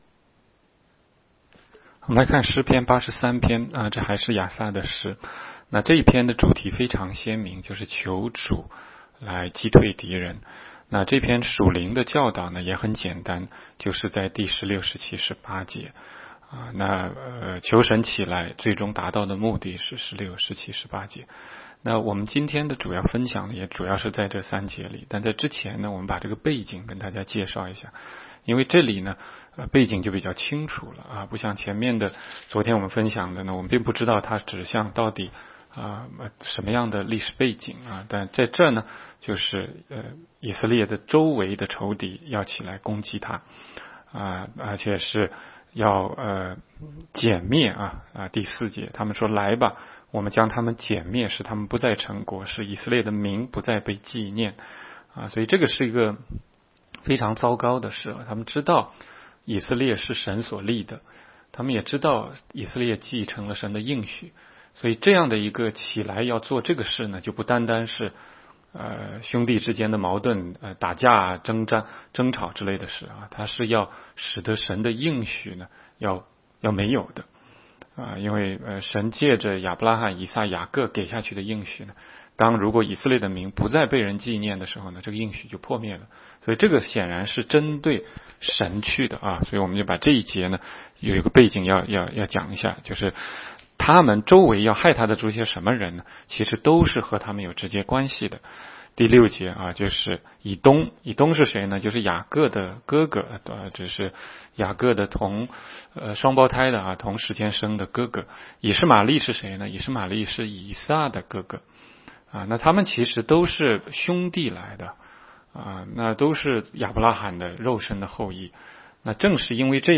16街讲道录音 - 每日读经-《诗篇》83章